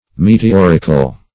Meteorical \Me`te*or"ic*al\, a.